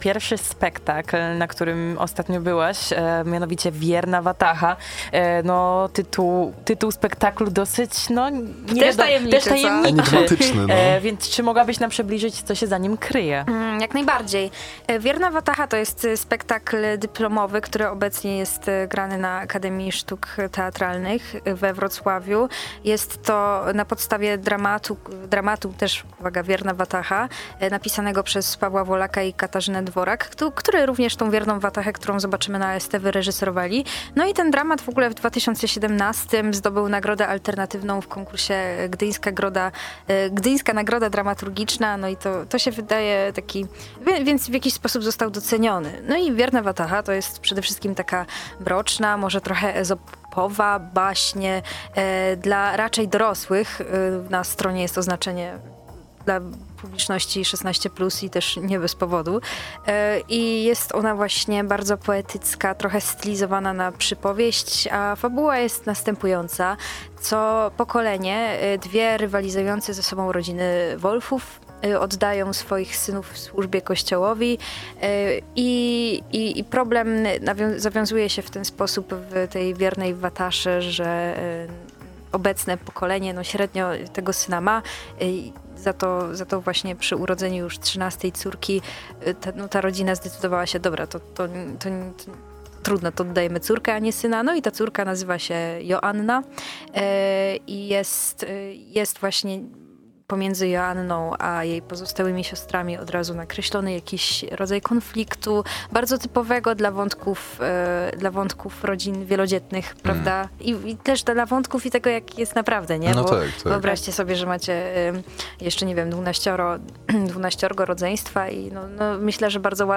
Wierna-wataha-recenzja.mp3